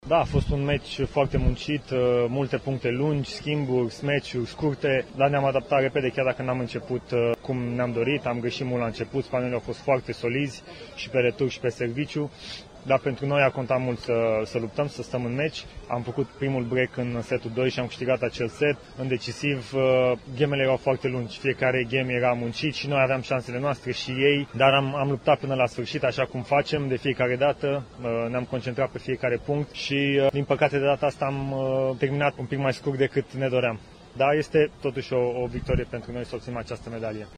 A 2 óra 26 perces játék után Horia Tecău elmondta, kemény ellenféllel találkoztak és a mérkőzés elején rosszul adogattak, de örülnek, hogy a spanyolok nem tudtak simán nyerni:
Horia-tecau.mp3